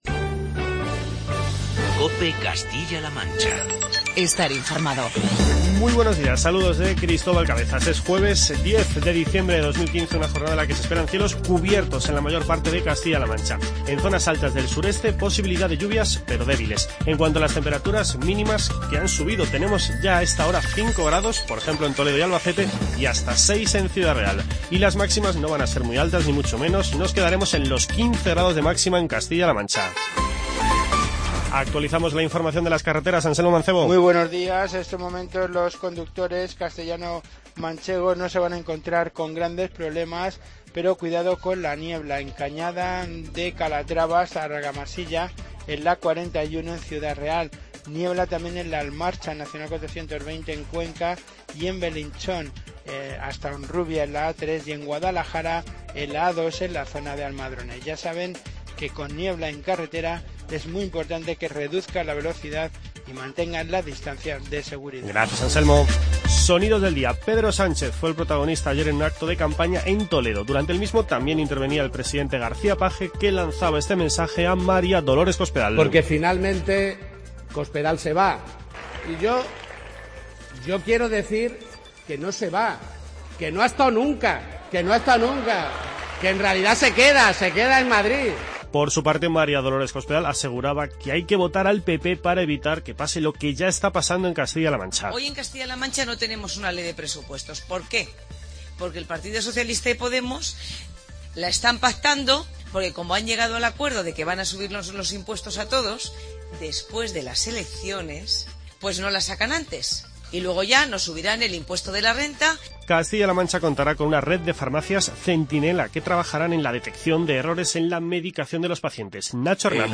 Informativo regional y provincial
Hoy destacamos, entre otros, los testimonios de Pedro Sánchez, María Dolores Cospedal y Nacho Hernando.